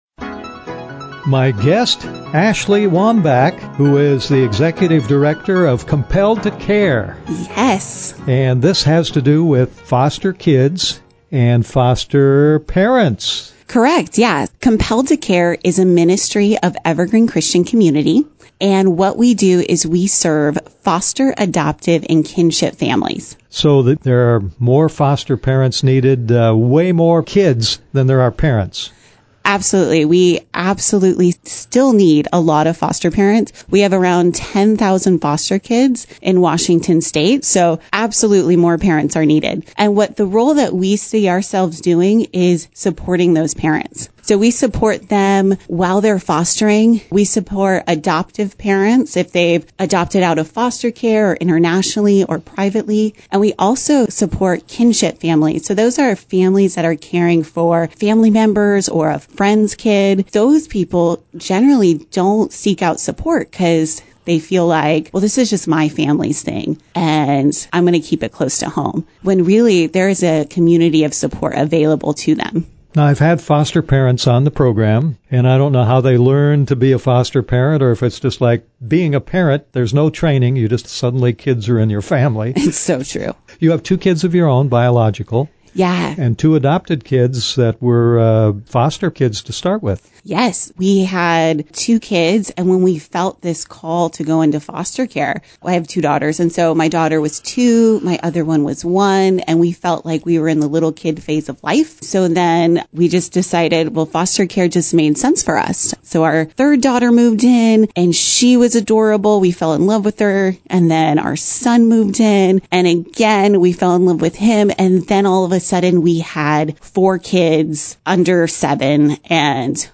KXXO 96.1 Radio Interview